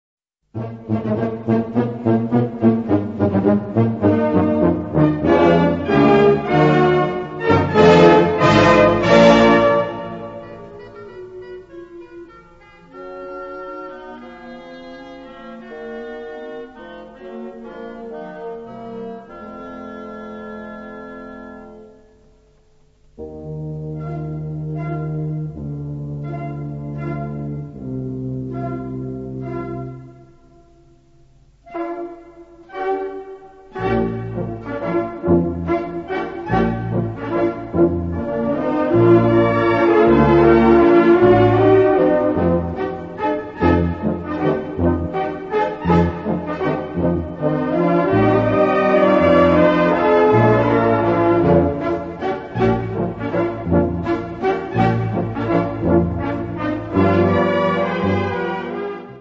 Gattung: Potpourri aus der Operette
Besetzung: Blasorchester